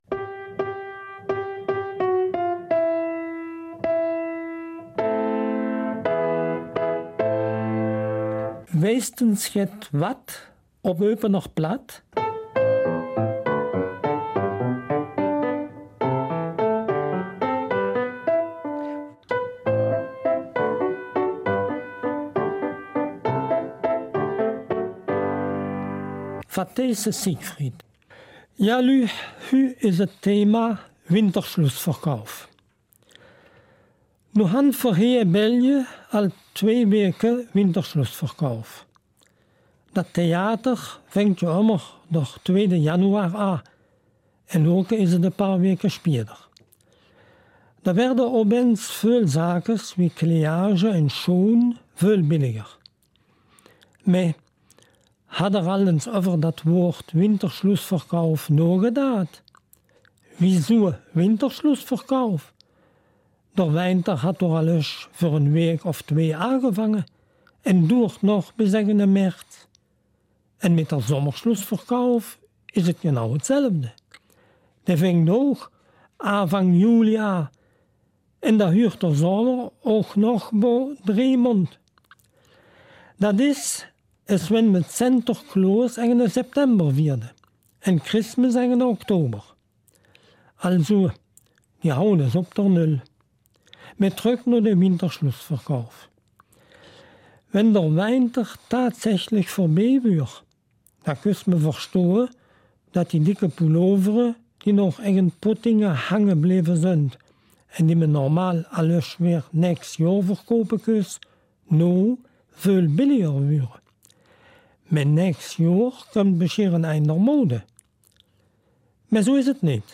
Eupener Mundart: Winterschlussverkauf und Sprachreichtum